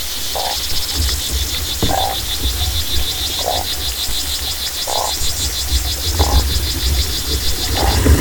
Below is an underwater recording of aquatic macroinvertebrates. The calls of these tiny river creatures, from the orders Hemiptera and Coleoptera, hum like cicadas. The sound is interspersed with the grunts of a fish (order Terapontidae), all set against the quiet backdrop of flowing water.
A grunting fish joins the chorus of aquatic invertebrates.
waterbug-chorus-with-fish-grunts.mp3